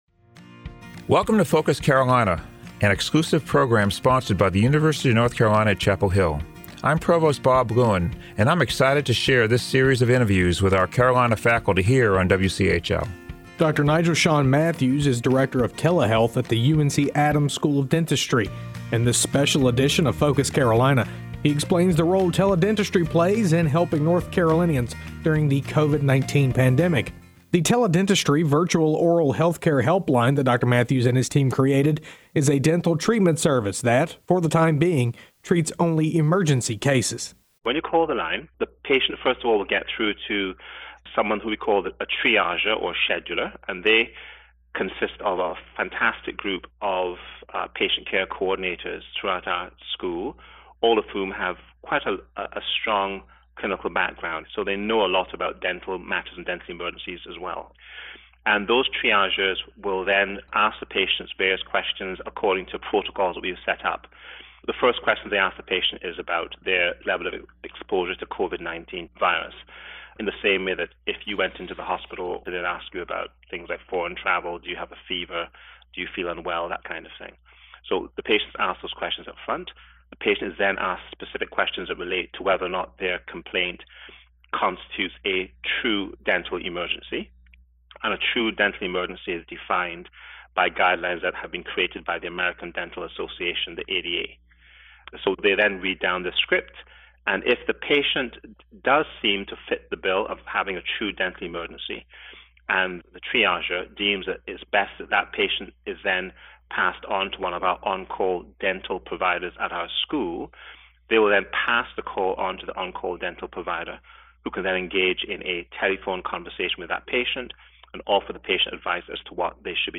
Tune in to Focus Carolina during morning, noon and evening drive times and on the weekends to hear stories from faculty members at UNC and find out what ignites their passion for their work. Focus Carolina is an exclusive program on 97.9 The Hill WCHL, sponsored by the University of North Carolina at Chapel Hill.